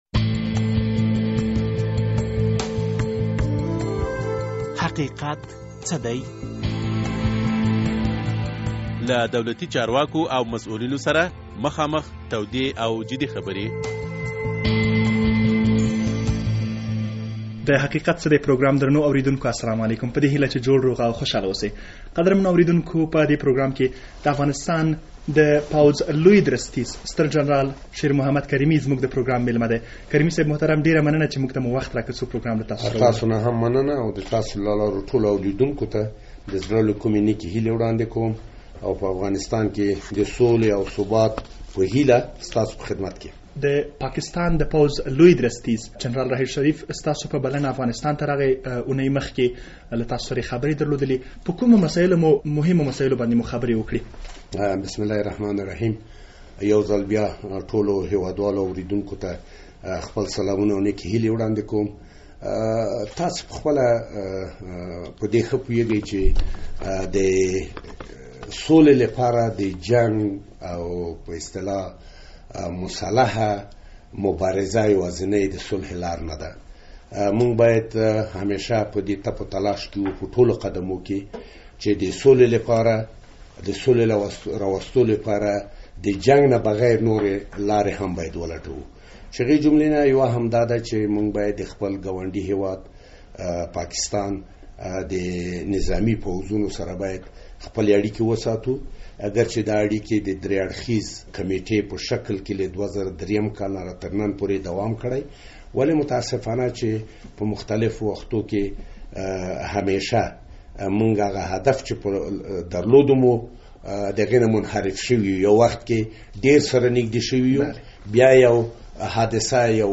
د حقیقت څه دی په دې پروګرام کې د افغانستان د پوځ له لوی درستیز ستر جنرال شیر محمد کریمي سره مرکه شوې.